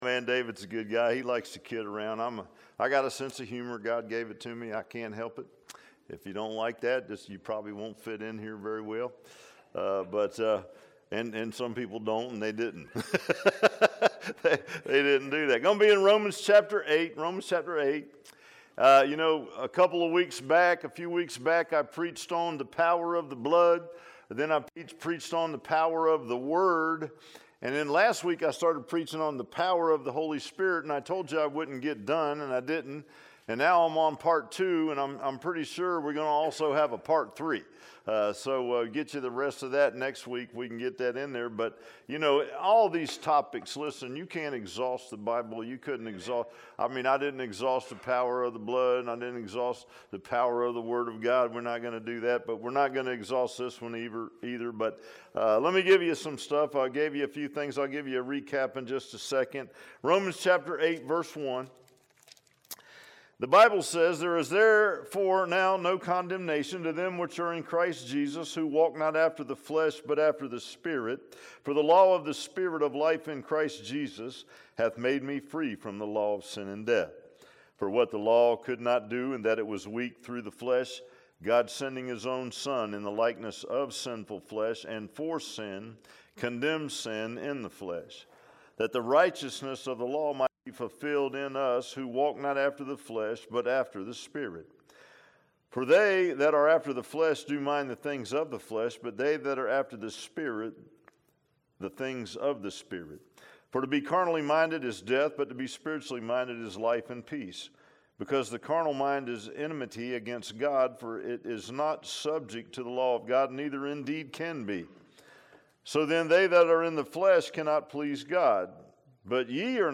Power of the Holy Spirit Passage: Romans 8:1-13 Service Type: Sunday PM « Revolutionary Faith If so be